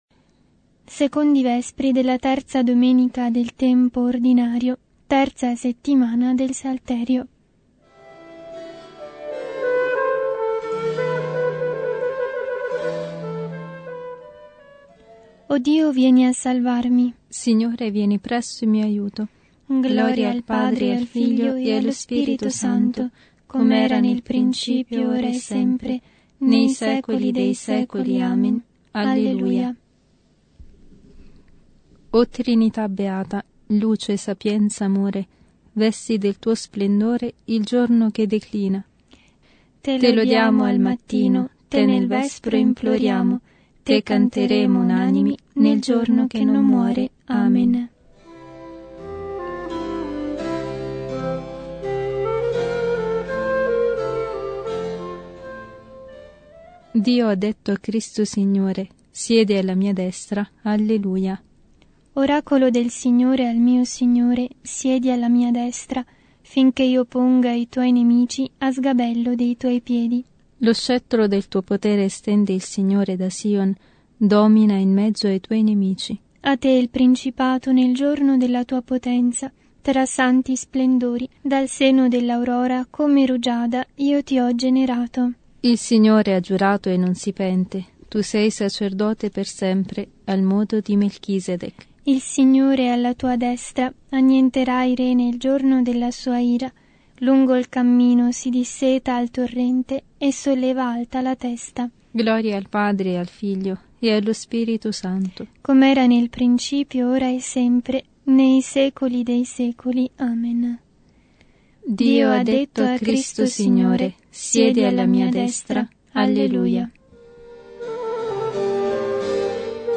Liturgia delle Ore